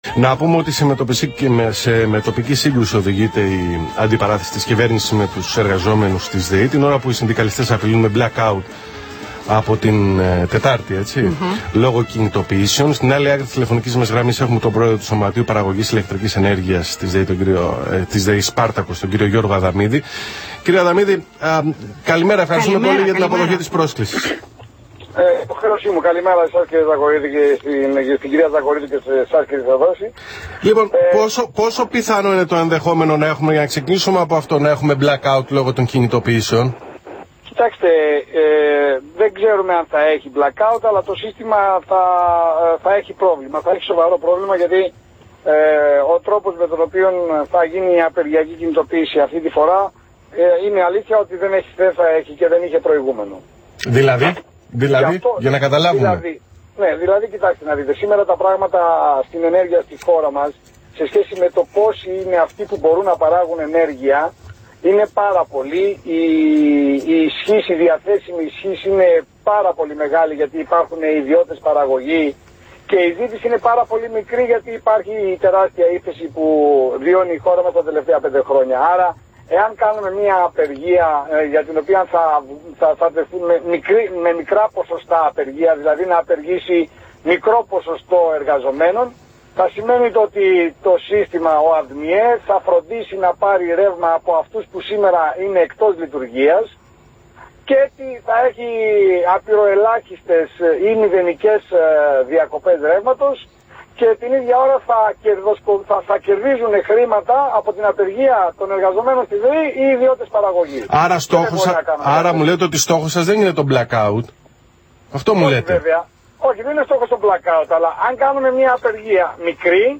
ΑΚΟΥΣΤΕ ΠΑΤΩΝΤΑΣ ΕΔΩ ΤΗΝ ΣΥΝΕΝΤΕΥΞΗ ΤΟΥ ΠΡΟΕΔΡΟΥΣΤΟ ΡΑΔΙΟΦΩΝΟ ΑΘΗΝΑ 9,84 ΓΙΑ ΤΗΝ ΠΩΛΗΣΗ ΤΗΣ ΔΕΗ